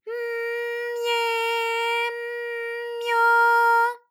ALYS-DB-001-JPN - First Japanese UTAU vocal library of ALYS.
my_m_mye_m_myo.wav